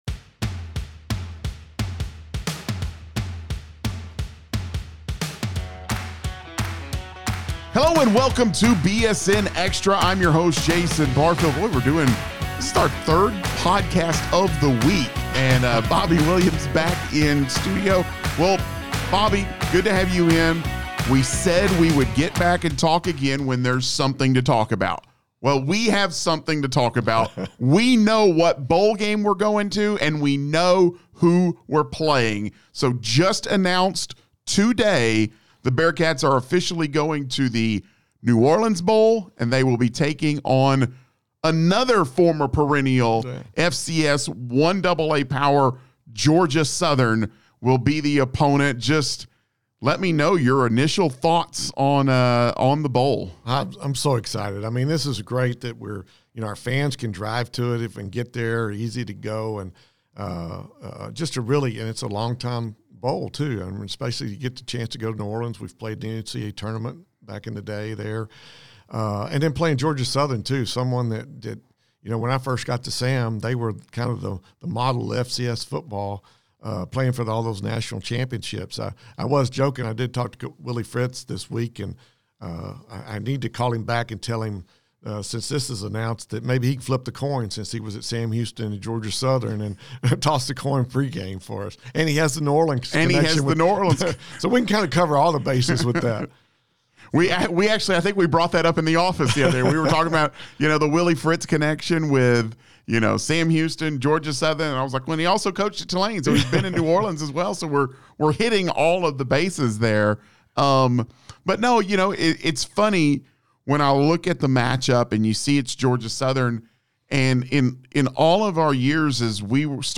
join in studio